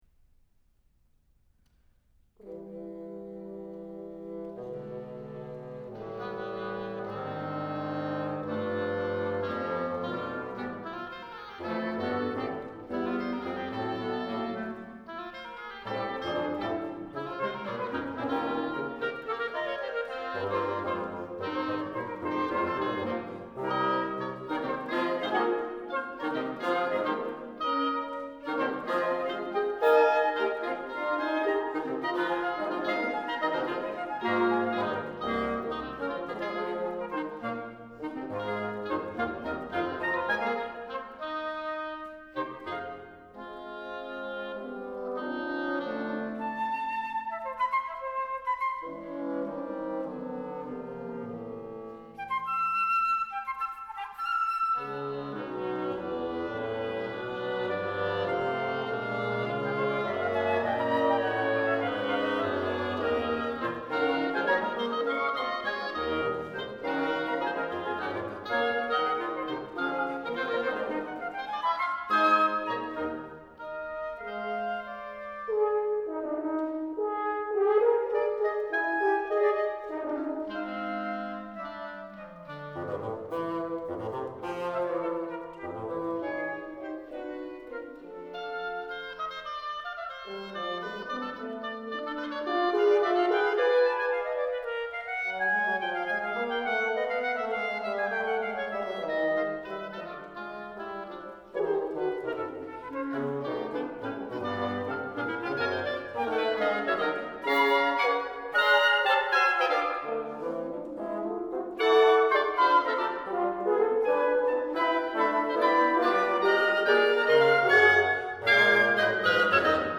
Wind Quintet (1996)
It is similar in many ways to my saxophone quartets: short and dense.
This part (the short series of duets) is probably my favorite.
This live recording is the work of five freshmen at Amherst and various other Five Colleges.